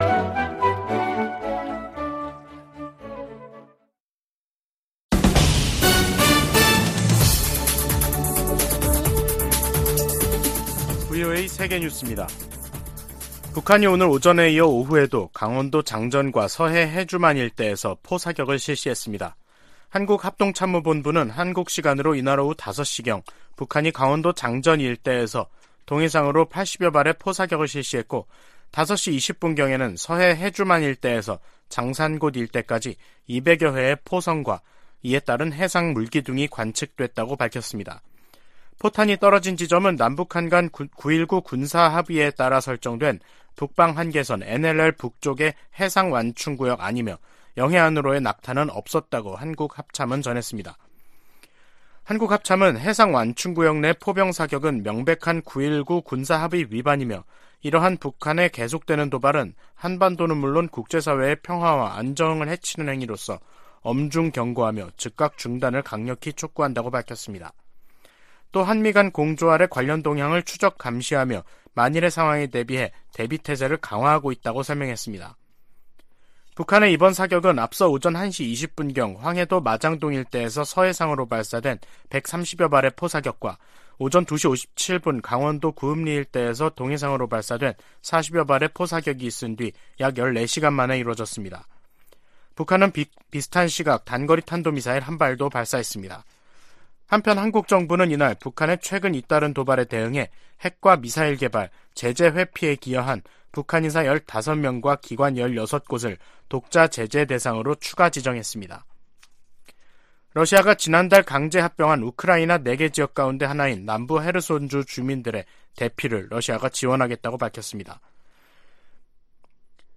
VOA 한국어 간판 뉴스 프로그램 '뉴스 투데이', 2022년 10월 14일 3부 방송입니다. 북한이 포 사격을 포함해 군용기 위협 비행, 탄도미사일 발사 등 무차별 심야 도발을 벌였습니다. 한국 정부가 북한의 노골화되는 전술핵 위협에 대응해, 5년 만에 대북 독자 제재에 나섰습니다.